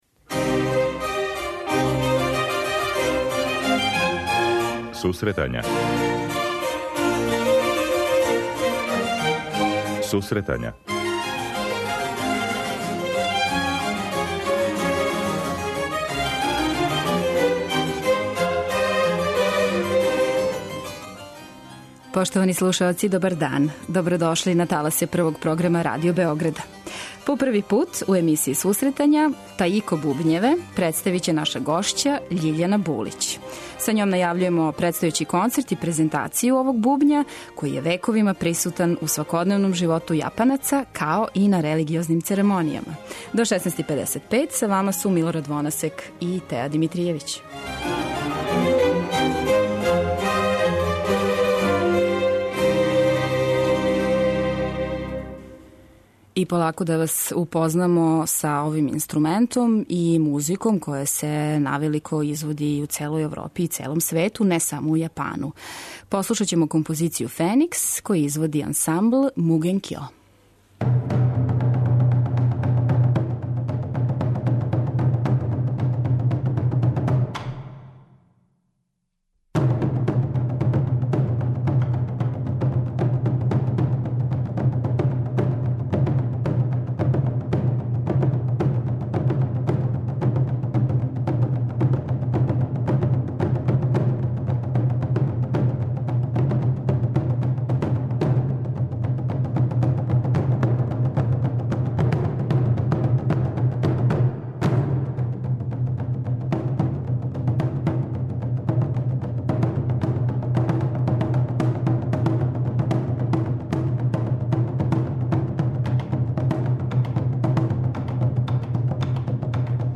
преузми : 28.13 MB Сусретања Autor: Музичка редакција Емисија за оне који воле уметничку музику.